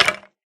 mob / skeletonhurt4